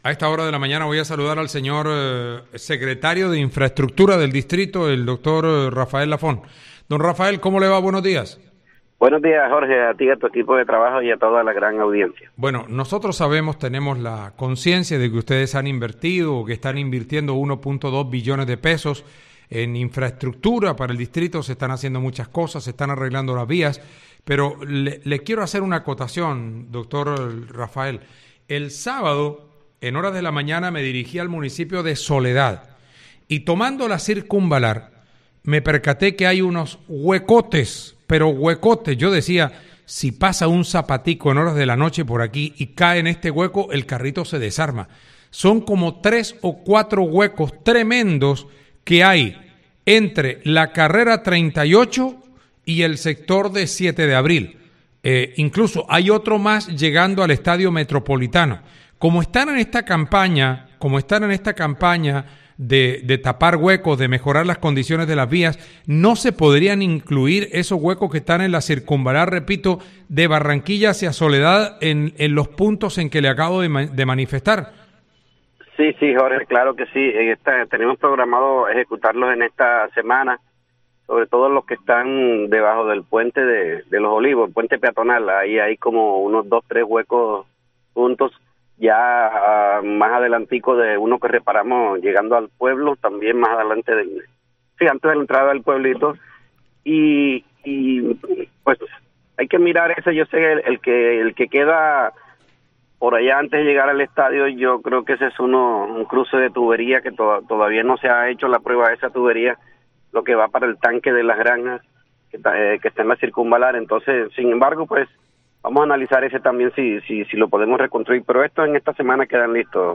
El secretario de Infraestructura, Rafael Lafont, afirmó que los trabajos se ejecutarán en la presente semana, reconociendo que el sector más deteriorado es debajo del puente en el barrio Los Olivos.